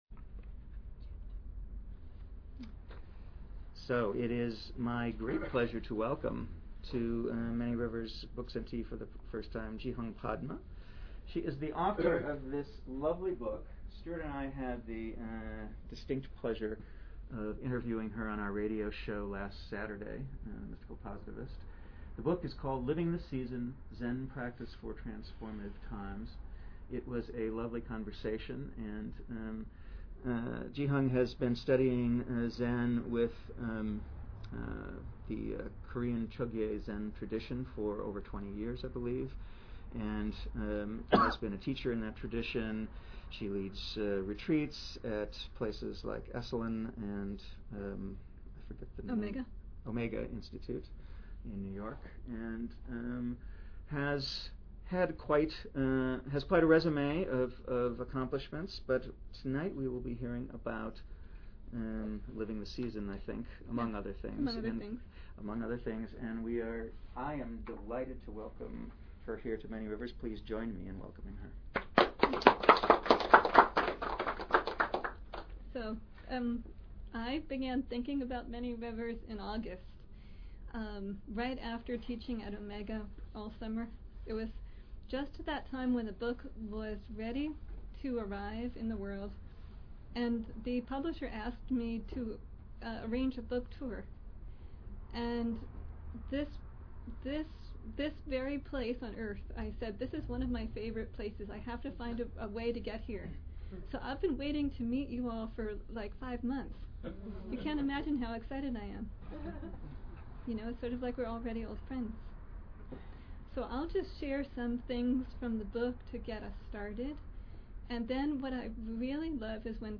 Archive of an event at Sonoma County's largest spiritual bookstore and premium loose leaf tea shop.
This evening combines Zen practice with creative approaches that support this attunement of mind/body with the world around us. We will use sacred ceremony and metta meditation to harness the power of group consciousness for the purposes of personal and societal transformation.